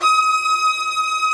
STR VIOLA0BR.wav